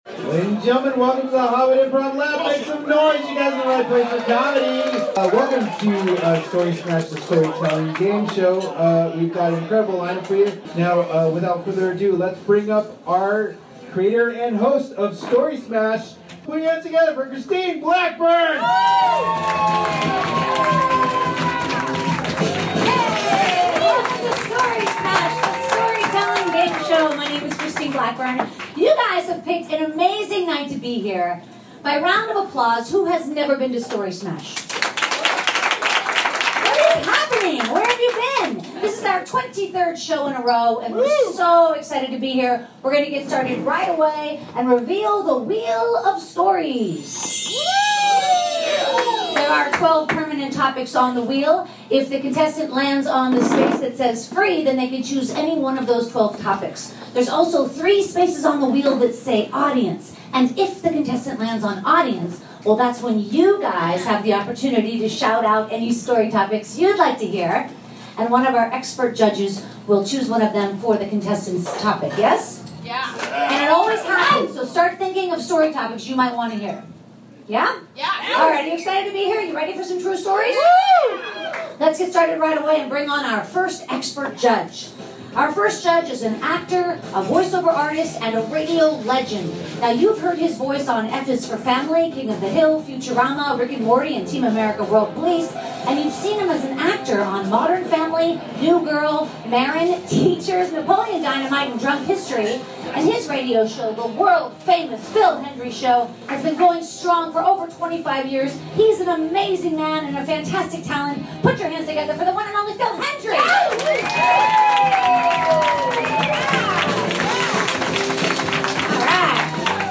566 - Story Smash the Storytelling Gameshow LIVE at The Hollywood Improv!
Recorded June 22nd at The Hollywood Improv.